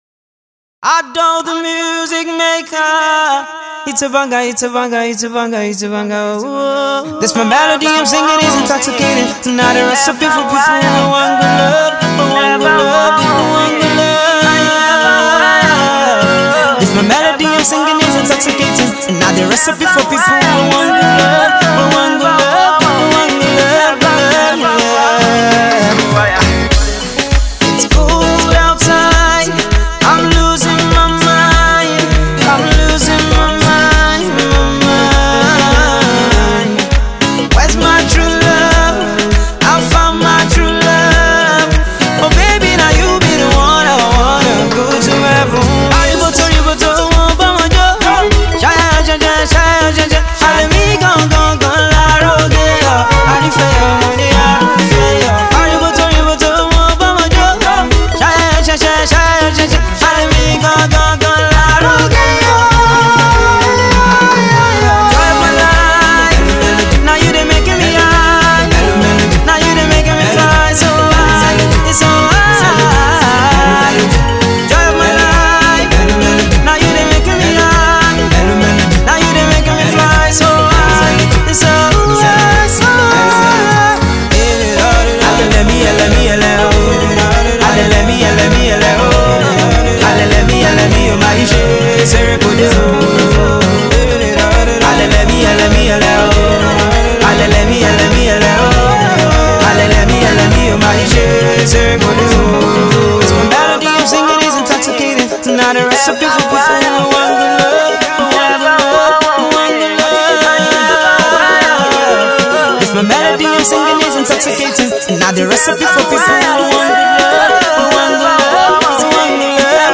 Alternative Pop
a beautiful love tune
The young man’s voice remains catchy and pleasant to ears .